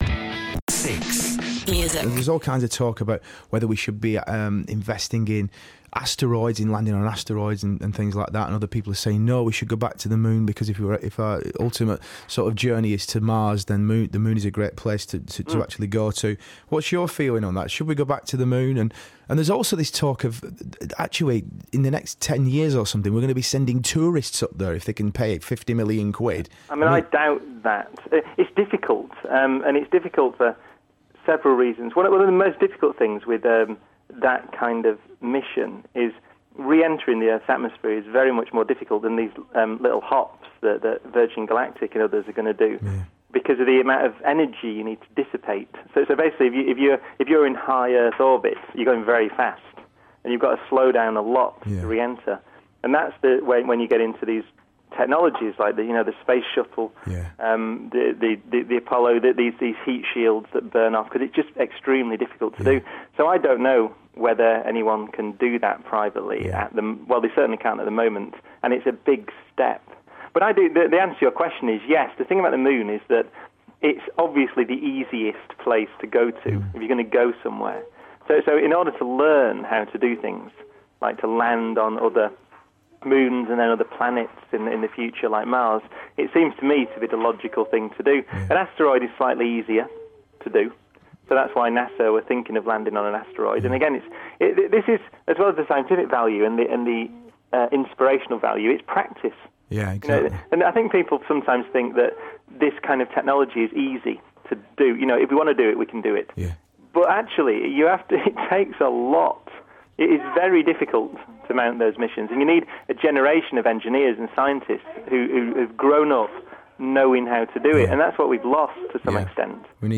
Brian, yes him from D:Ream, was on Shaun Keaveny this morning discussing whether or not we should be landing on asteroids, if space tourism will catch on and the importance of going back to moon.